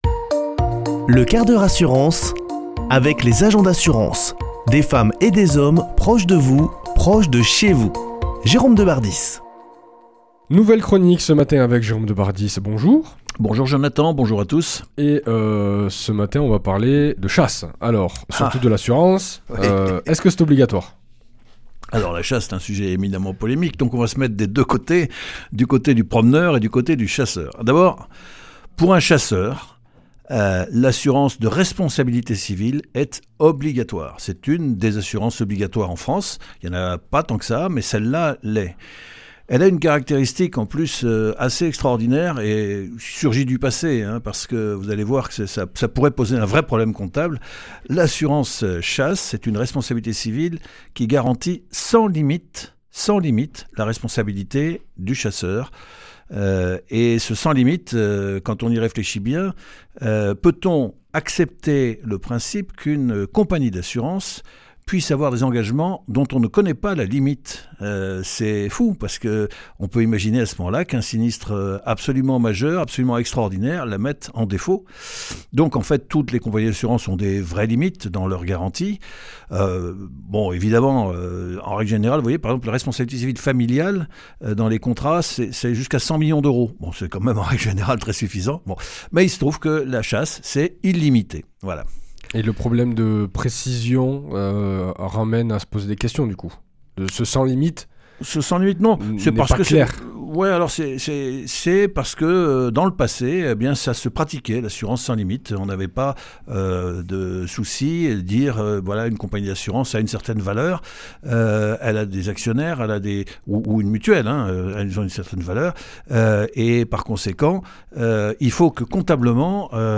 Chroniqueur